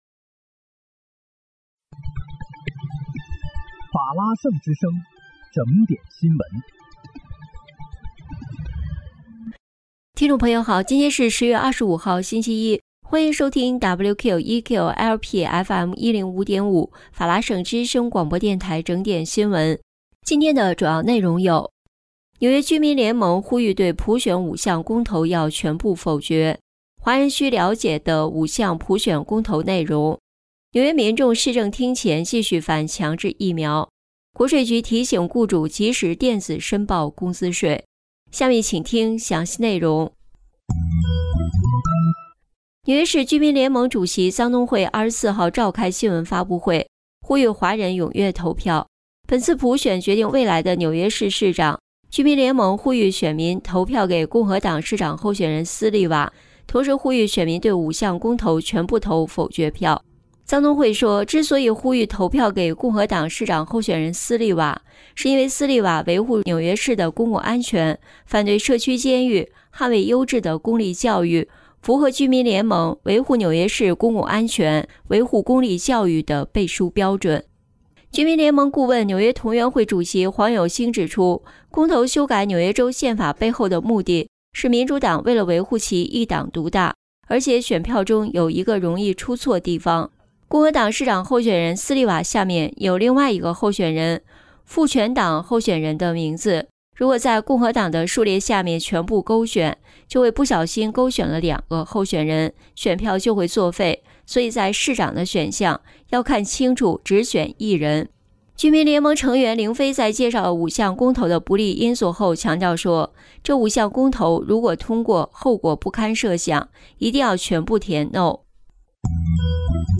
10月25日（星期一）纽约整点新闻